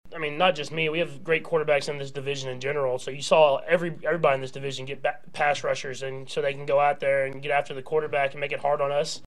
Chiefs quarterback Patrick Mahomes on the AFC West having great quarterbacks.